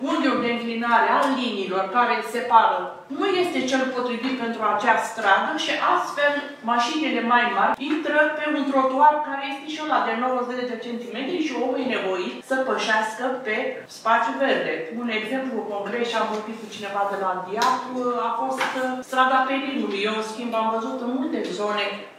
Azi, 27 februarie 2025, Consiliul Local Tulcea s-a reunit într-o nouă ședință ordinară pentru a discuta o serie de proiecte ce vizează, printre altele, administrarea domeniului public, cofinanțarea serviciilor sociale destinate persoanelor fără adăpost și măsuri fiscale pentru contribuabilii aflați în dificultate.